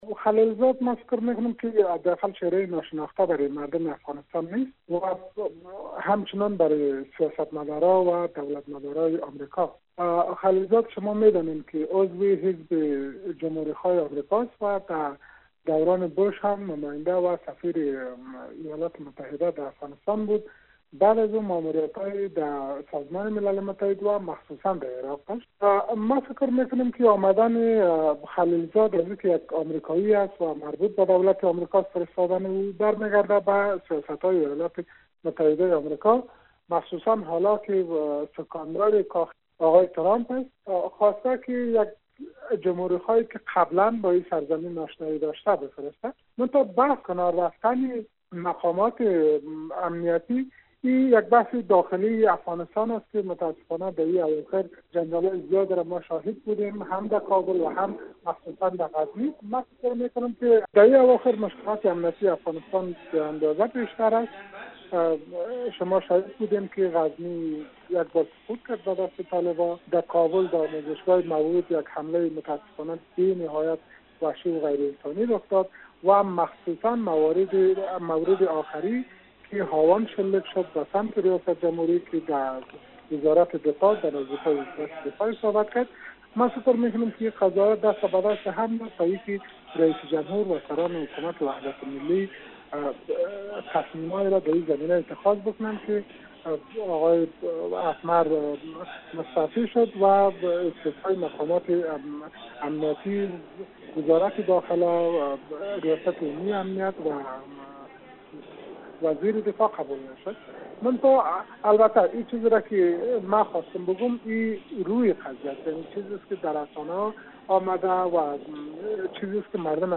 کارشناس سیاسی افغان گفت: امریکا و حکومت افغانستان می خواهند که این حکومت یکدست باشد و برنامه های هماهنگی را پیش ببرد اما در ظاهر امریکایی ها نمی توانند آشکارا در امور این کشور دخالت کنند.